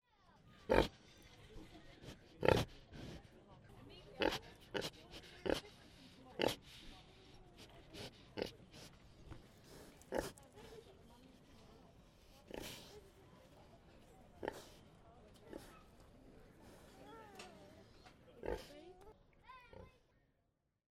A mummy pig at Surrey Docks Farm on the Thames Cycle Path. London